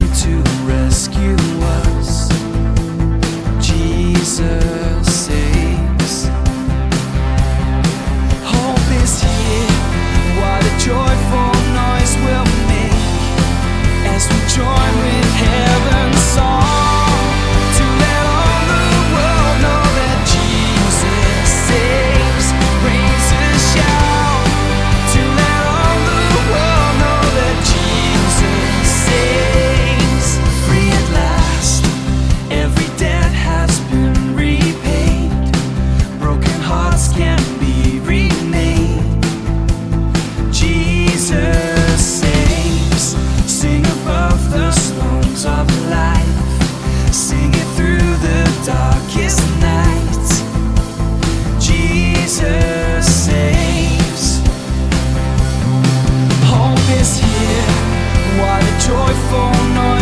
neuen Anbetungslieder
• Sachgebiet: Praise & Worship